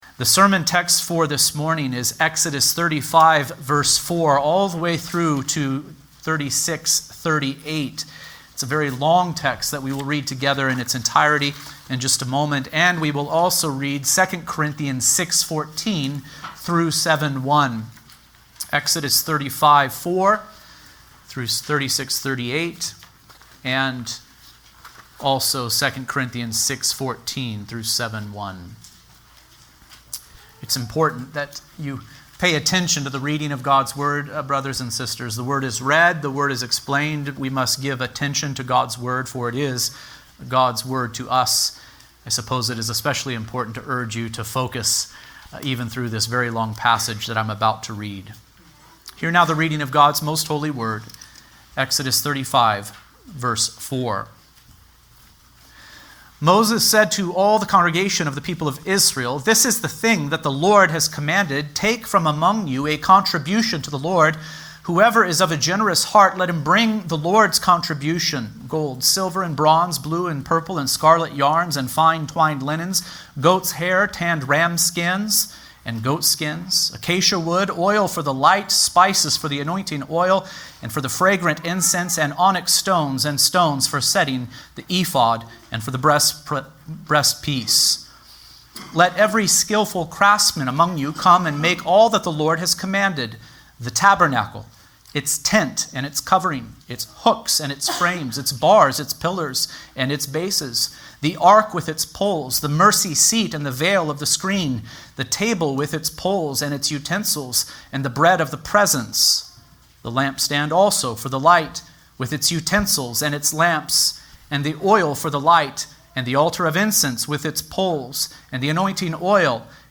The Gospel Of The Tabernacle | SermonAudio Broadcaster is Live View the Live Stream Share this sermon Disabled by adblocker Copy URL Copied!